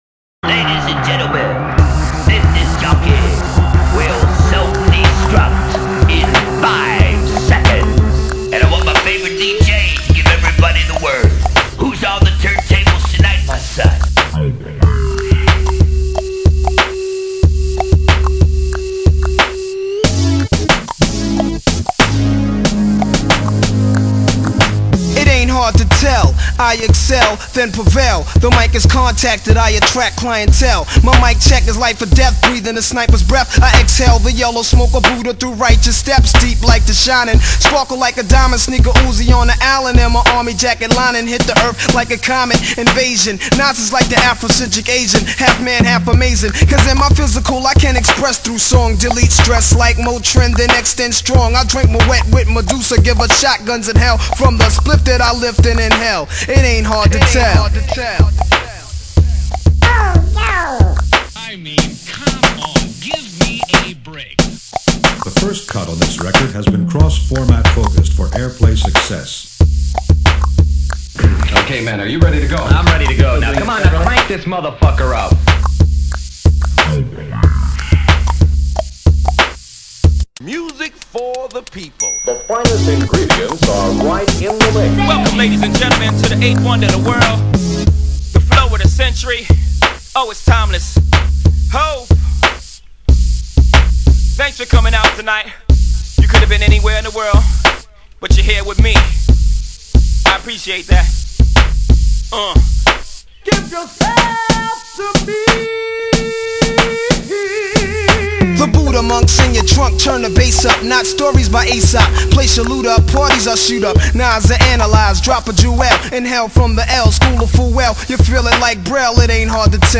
DJ Mixes